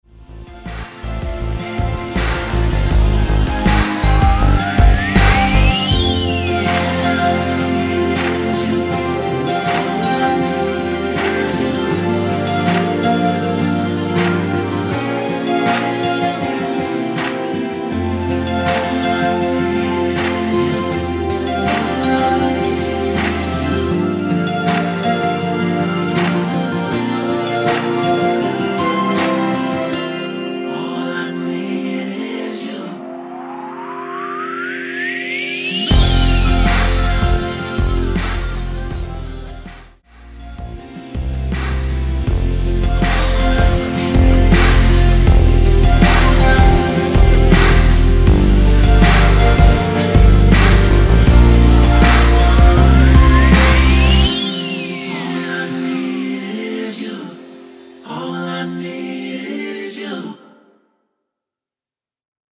(-2)내린 코러스 포함된 MR 입니다.(미리듣기 참조)
앞부분30초, 뒷부분30초씩 편집해서 올려 드리고 있습니다.
중간에 음이 끈어지고 다시 나오는 이유는
곡명 옆 (-1)은 반음 내림, (+1)은 반음 올림 입니다.